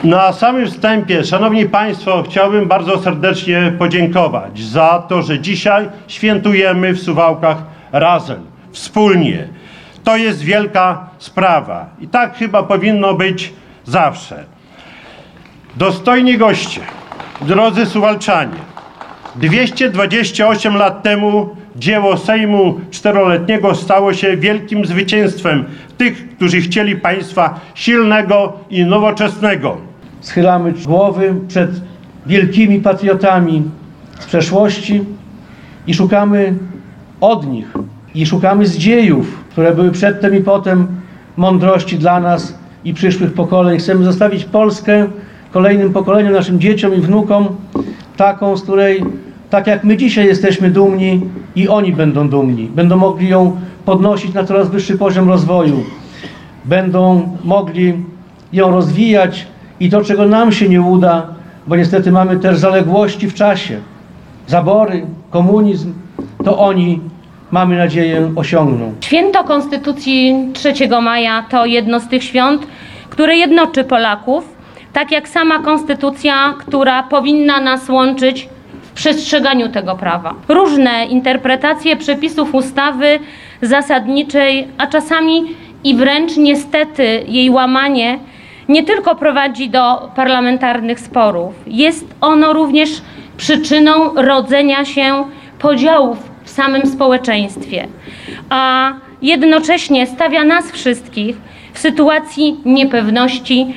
Była msza święta w miejscowej konkatedrze i okolicznościowe wystąpienia w Parku Konstytucji 3 Maja w centrum miasta.
Głos zabrali Czesław Renkiewicz, prezydent miasta, Jarosław Zieliński, poseł Prawa i Sprawiedliwości oraz sekretarz stanu w Ministerstwie Spraw Wewnętrznych i Administracji i Bożena Kamińska, parlamentarzystka Platformy Obywatelskiej. Przywoływali wydarzenia sprzed 228 lat, mówili o Konstytucji 3 Maja, patriotyzmie, jedności.